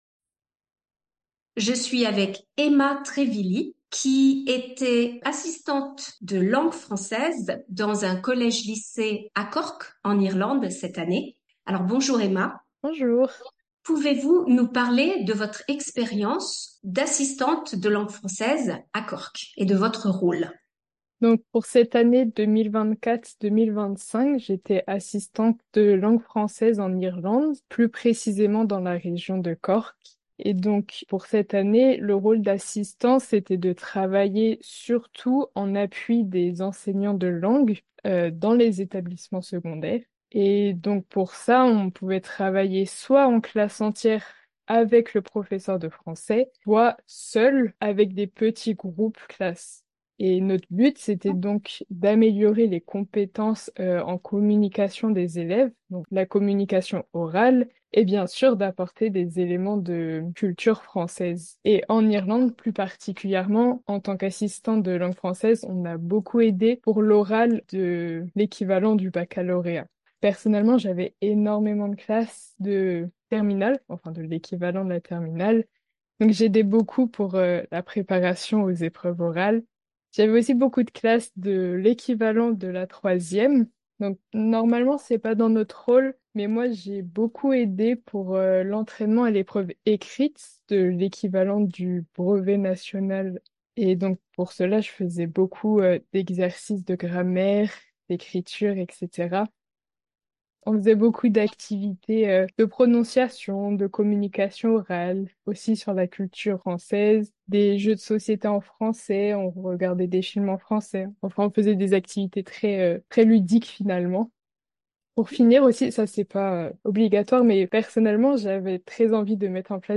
Interview in French
Entretien